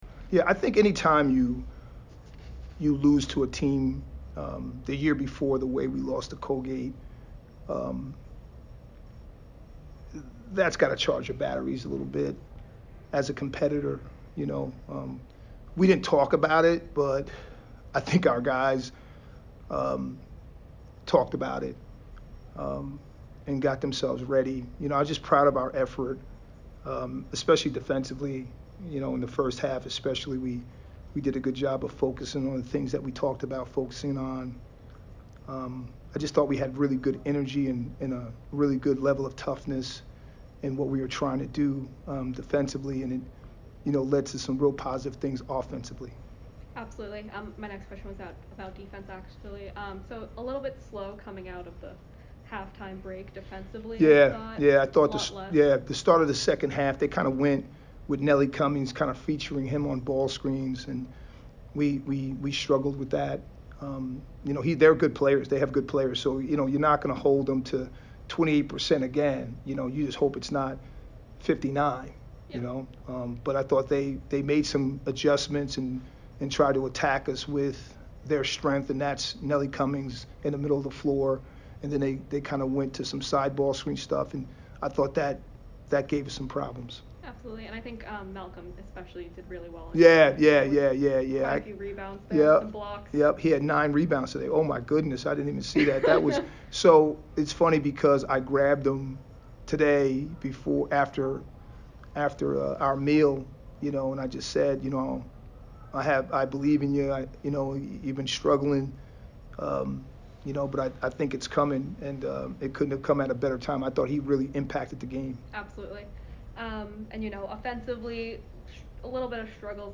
Colgate MBB Press Conference